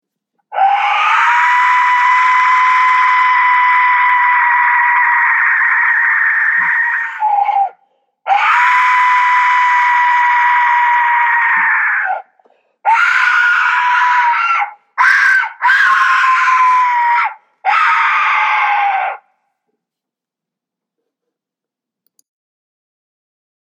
Aztec Death Whistle shape shifter